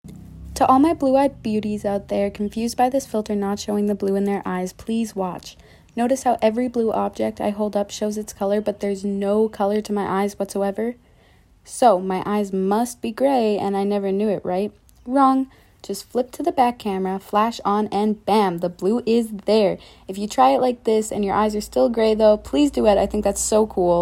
Uh sound effects free download